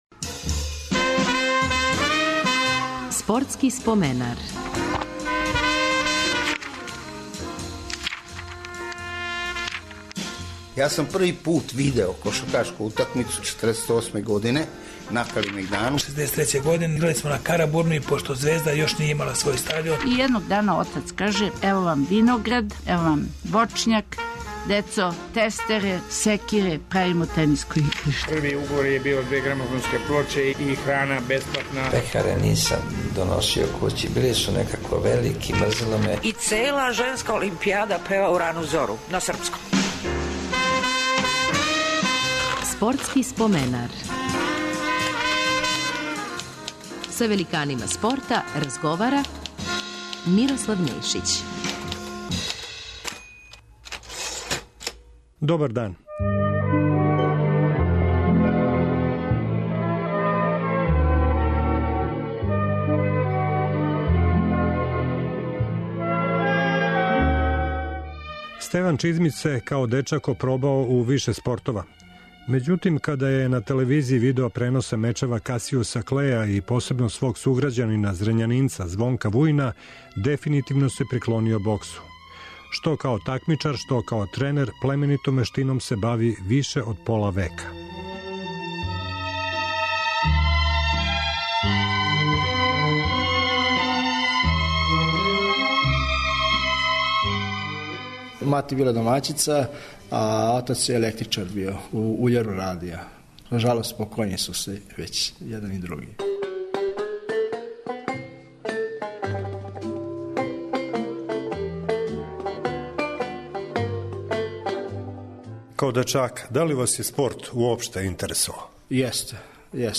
Гост емисије је боксер